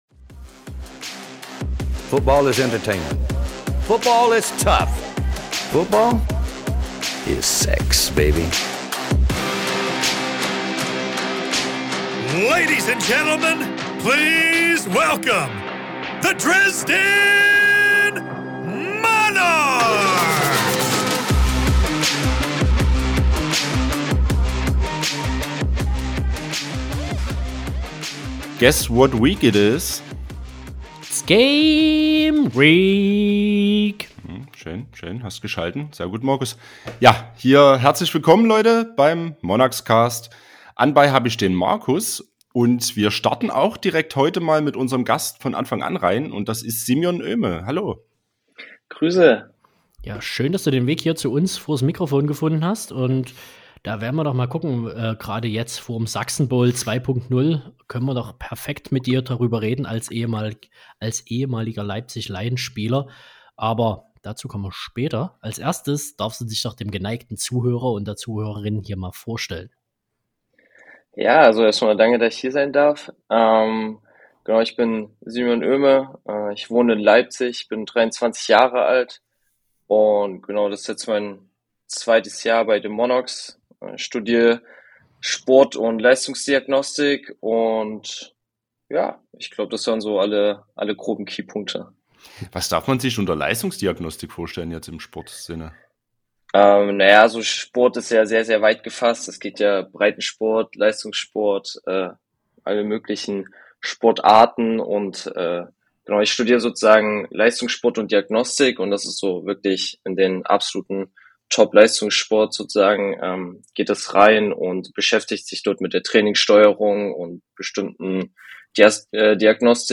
Offseason-Zeit ist Interview-Zeit.